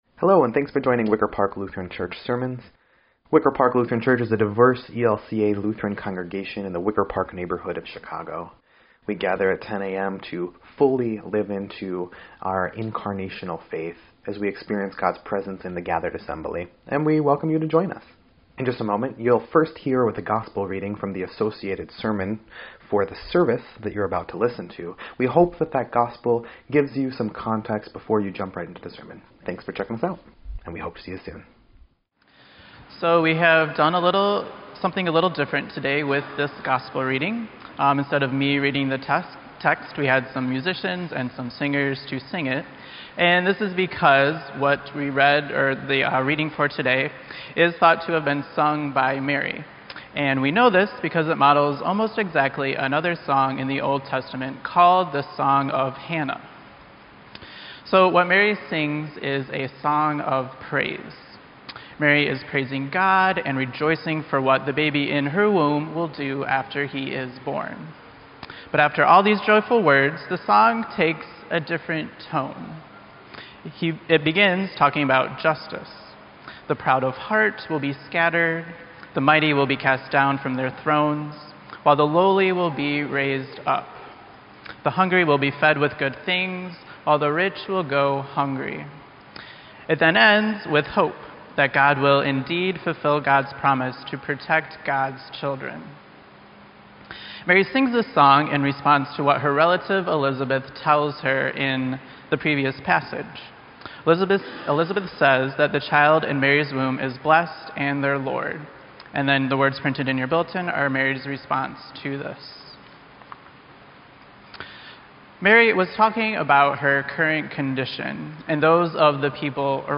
EDIT_Sermon_8_5_18-1.mp3